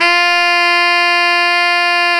Index of /90_sSampleCDs/Roland L-CD702/VOL-2/SAX_Tenor mf&ff/SAX_Tenor ff
SAX TENORF0K.wav